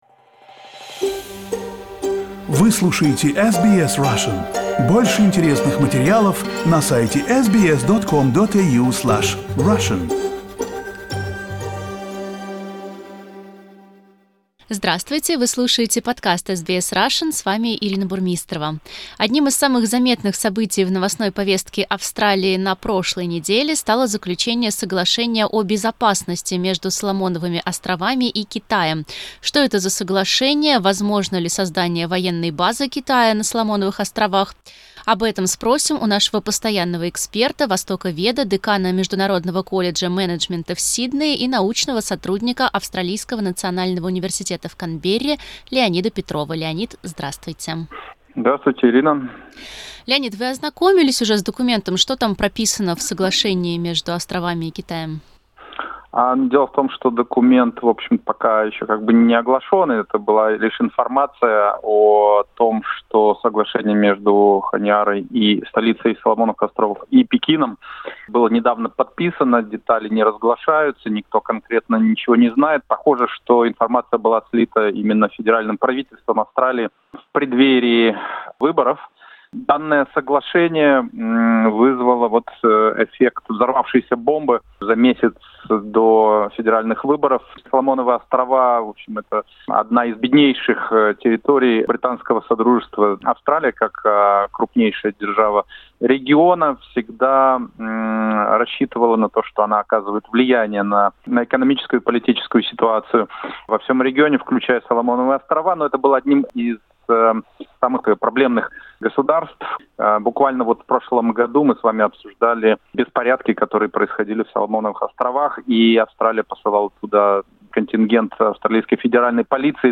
Interview with orientalist